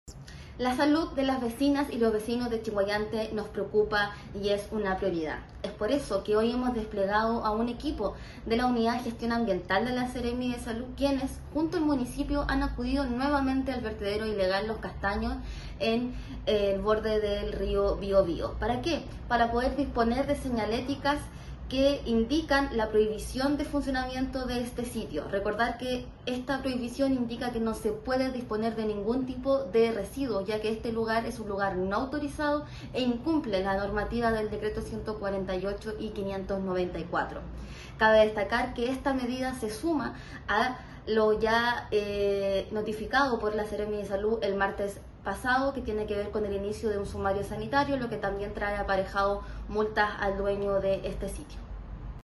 Por su parte, la seremi de Salud (s), Javiera Ceballos, explicó el alcance de la medida sanitaria y recordó que esta acción “se suma a la notificación del inicio de un sumario sanitario, la semana pasada”.